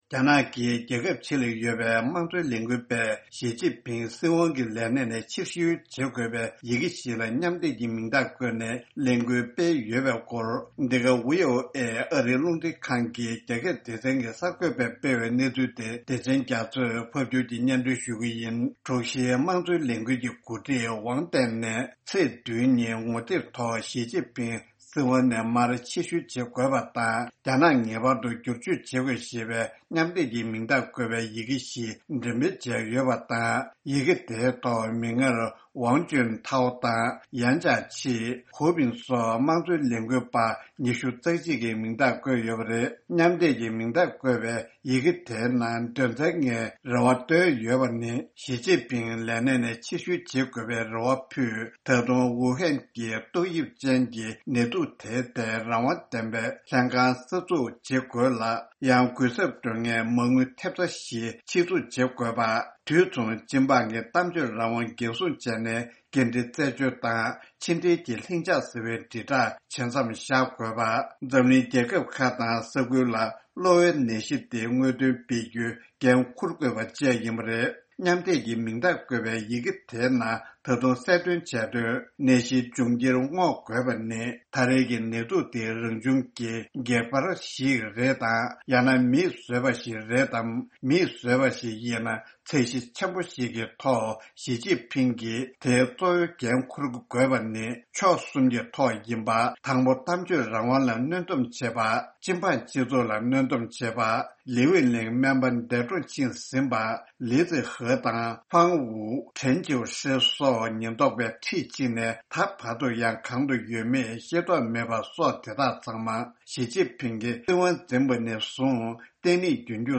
༄༅།། ཕྱི་རྒྱལ་ཡུལ་གྲུ་ཁག་ཏུ་གནས་འཁོད་རྒྱ་ནག་གི་དམངས་གཙོ་དོན་གཉེར་བ་ཚོས་ཞིས་ཅིན་ཕིང་རྒྱ་ནག་གི་སྲིད་དབང་ནས་ཕྱི་བཤོལ་བྱ་དགོས་ཞེས་མིང་རྟགས་བསྡུ་རུབ་ཀྱི་ལས་འགུལ་ཞིག་སྤེལ་འགོ་ཚུགས་ཡོད་པའི་སྐོར། འདི་ག་ཨ་རིའི་རླུང་འཕྲིན་ཁང་གི་རྒྱ་སྐད་སྡེ་ཚན་གྱི་གསར་འགོད་པས་སྤེལ་བའི་གནས་ཚུལ་